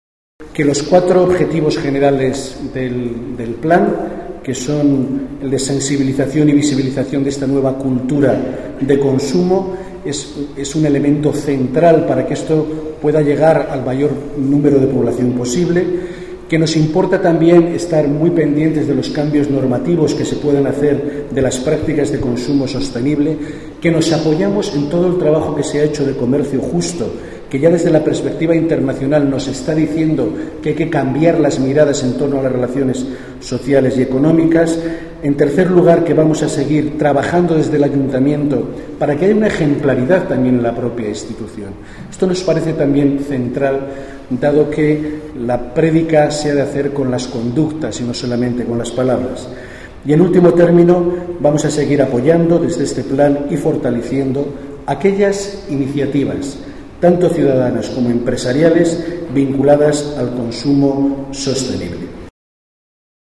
Más archivos multimedia Manuela Carmena, alcaldesa de Madrid Javier Barbero, delegado Salud, Seguridad y Emergencias. Objetivos nuevo consumo Javier Barbero, delegado Salud, Seguridad y Emergencias.